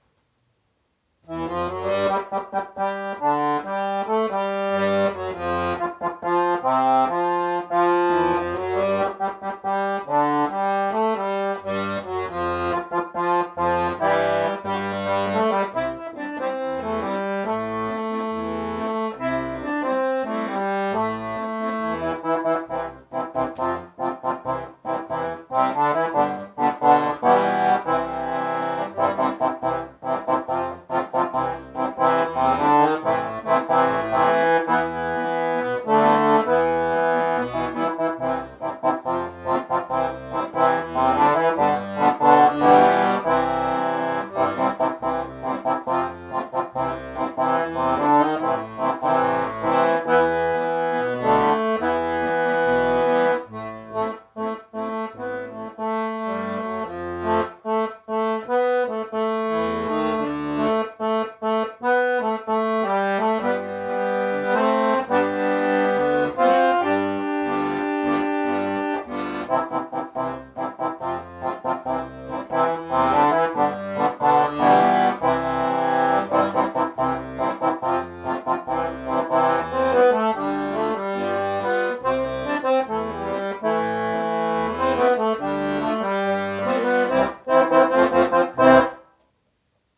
Hallo, hier die Aufnahme in Mono-Handy, da ich den Tascam-Recorder nicht gefunden habe Zum vorherigen Rätsel noch: ich hatte nicht gedacht, dass das eine "neue" Eigenkomposition ist...